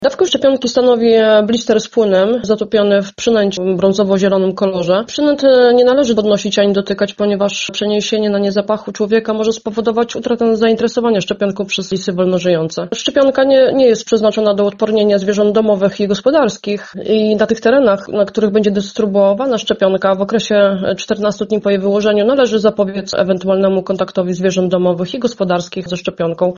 – Nie należy dotykać szczepionek – mówi Lubelski Wojewódzki Lekarz Weterynarii, Agnieszka Smyl.